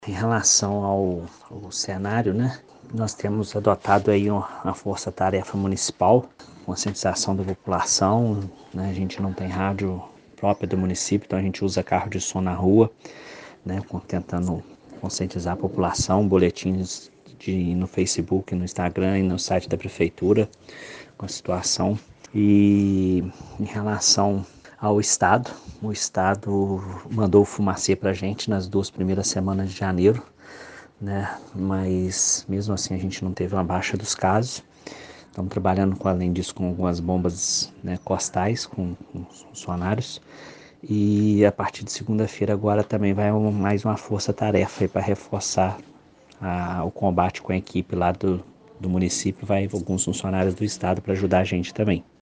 O Secretário Municipal de Saúde, Roger Hungria de Paula, explica as ações que estão sendo realizadas no município para combater o Aedes aegypti.
Secretario-saude-Piau-aedes-aegypti-1.mp3